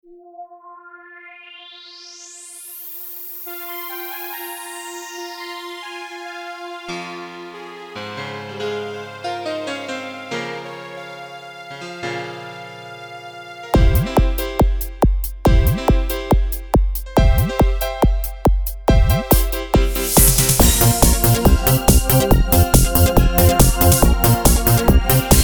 • Жанр: Поп
• Жанр: Легкая
Шальная песенка в стиле старого доброго диско!